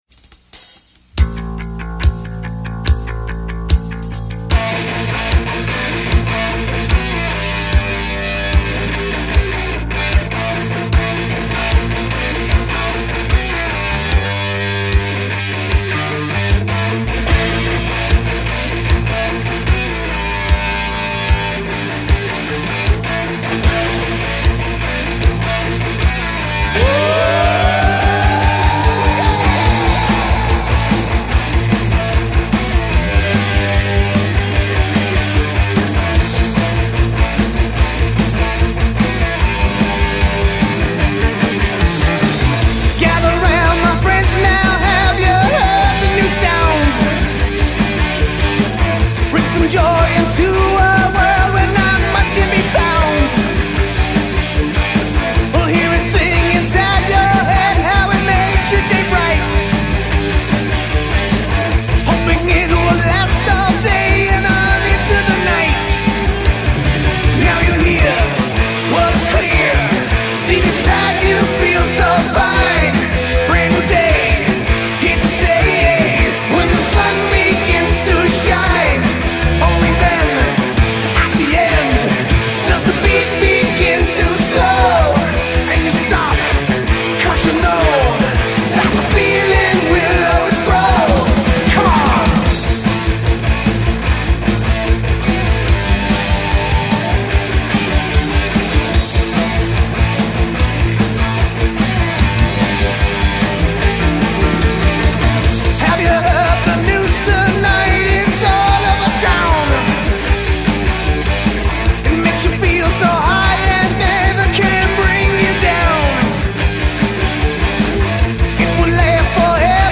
MASTER RECORDINGS - Rock
Jersey Rock group